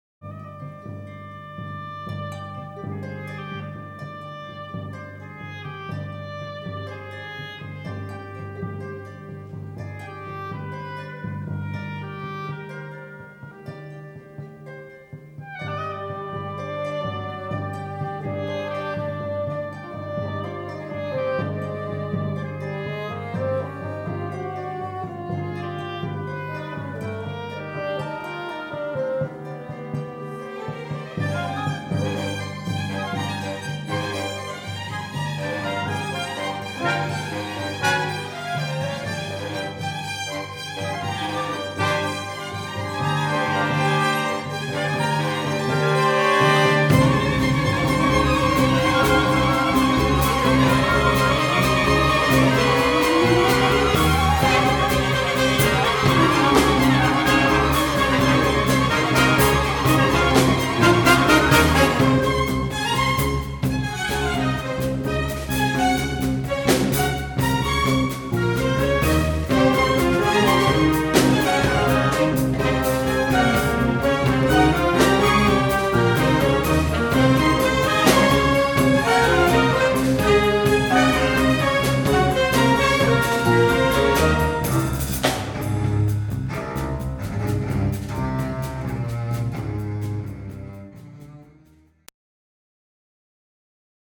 Orchestral Excerpt